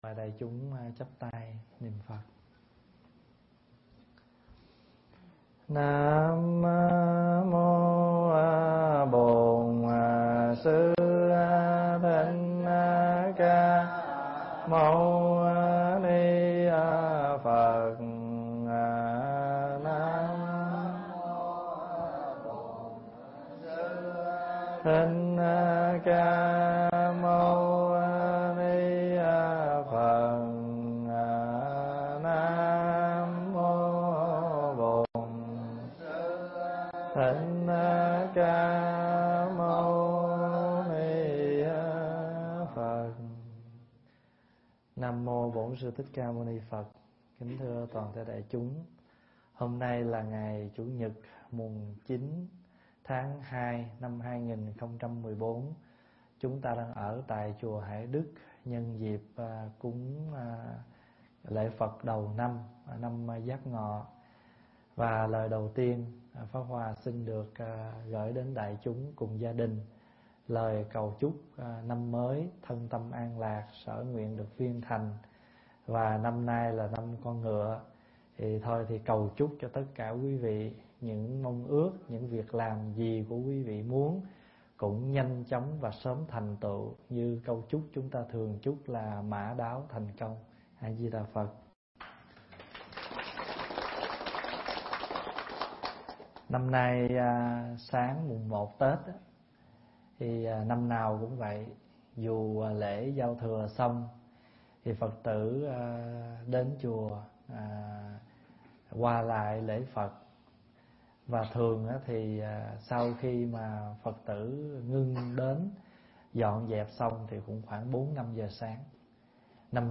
Tải mp3 Thuyết Giảng Tuấn Mã Thẳng Tiến
thuyết giảng tại Chùa Hải Đức, Canada